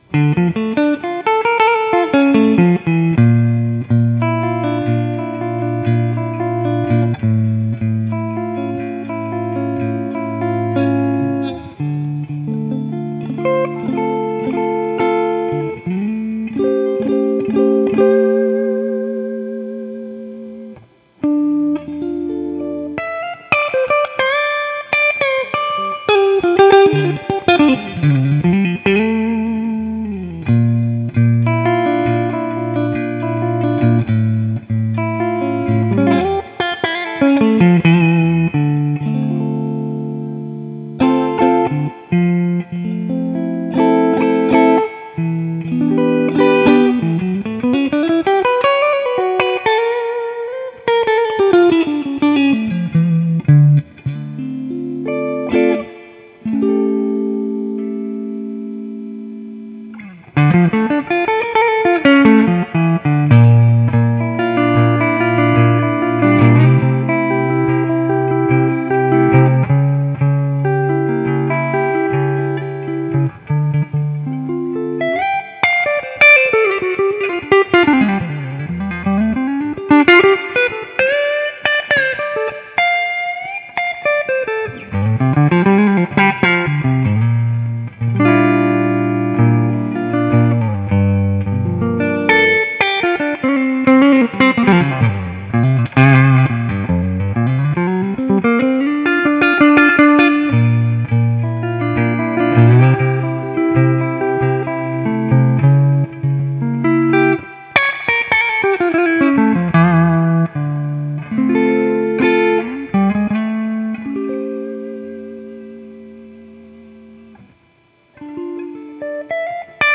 Ίσως έπρεπε να ανοίξω καινούριο θέμα και να το κάνω σε στυλ κουίζ αλλά δεν πολυέχει νόημα, οπότε ιδού το ίδιο σήμα περασμένο από 3 amp sims και επίσης γραμμένο με μικρόφωνο από τον Deluxe Reverb μου: Amplitube '65 Fender Deluxe Reverb: